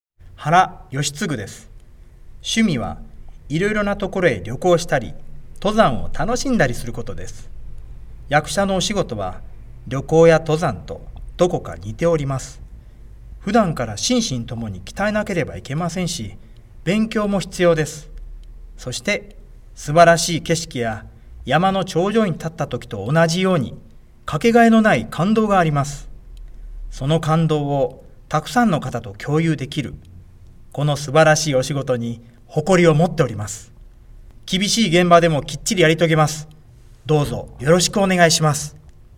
出⾝地・⽅⾔ 大阪府・関西弁
ボイスサンプル